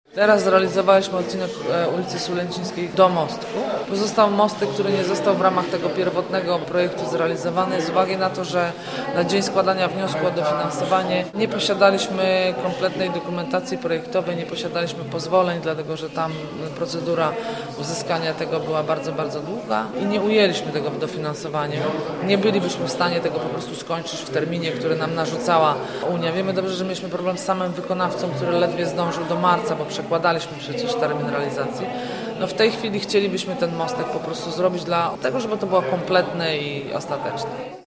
Zakończona w marcu przebudowa ulicy Sulęcińskiej nie objęła tego miejsca. Dlaczego? – tłumaczy wiceprezydent Agnieszka Surmacz: